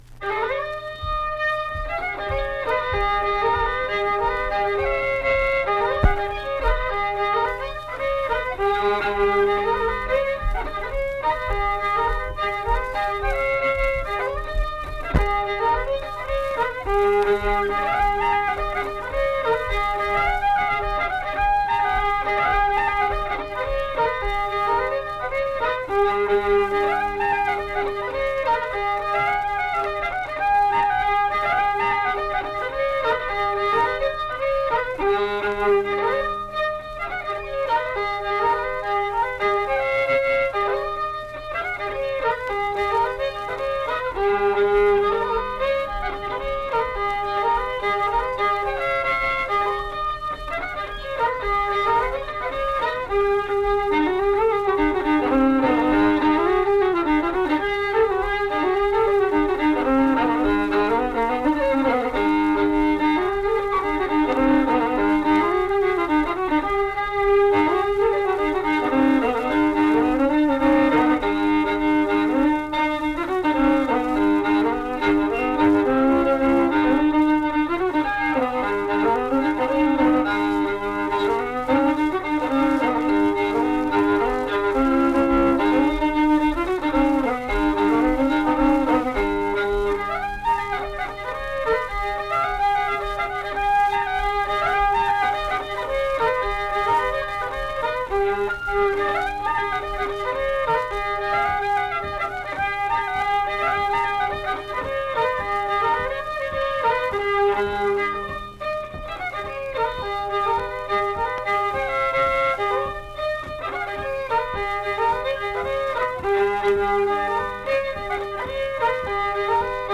Unaccompanied fiddle music
Instrumental Music
Fiddle
Pocahontas County (W. Va.), Mill Point (W. Va.)